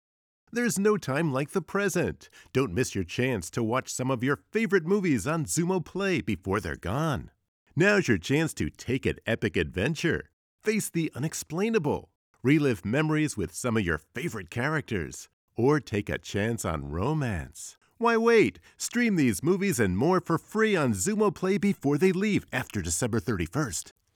I am a male voiceover talent with over 20 years of VO experience .
Commercial Demo
English - USA and Canada
Middle Aged